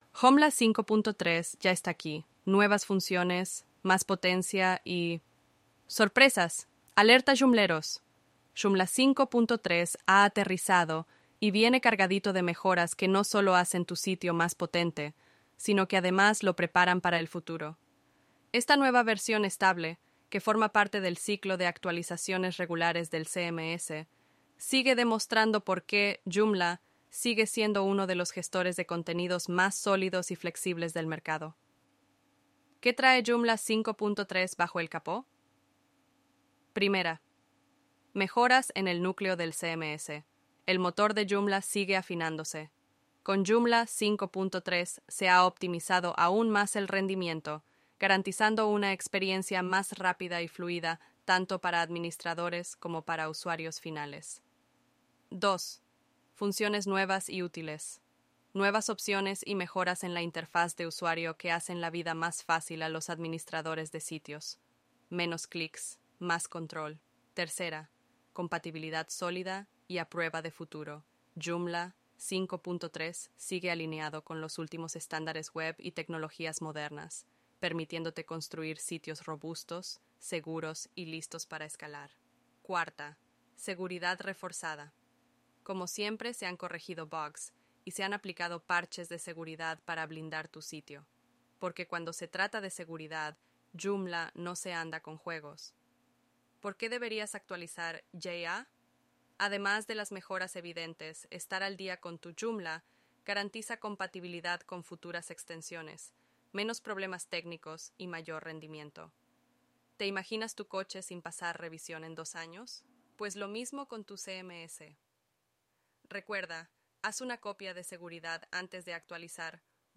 Podcast sobre Joomla en español con JoomlIA Robers, una IA